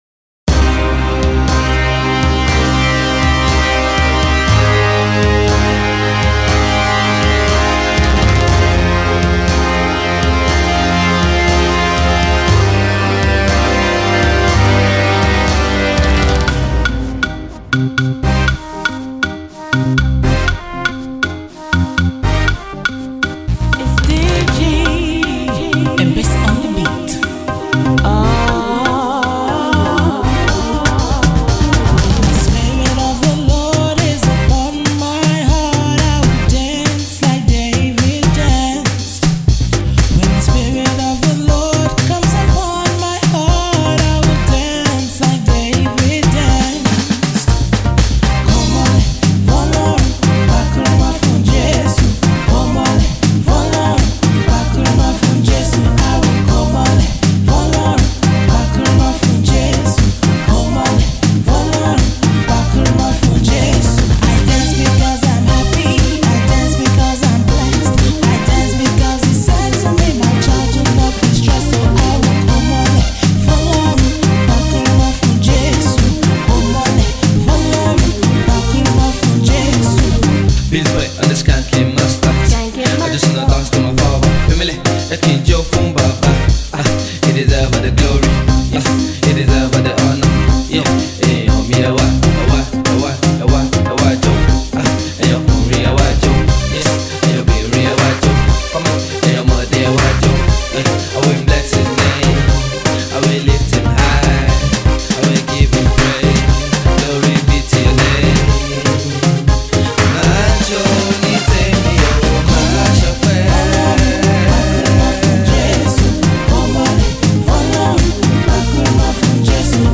first studio single